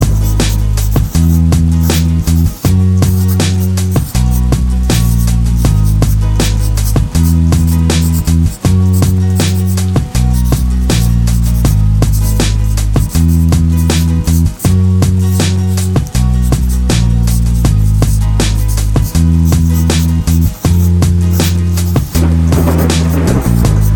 Duet Version Pop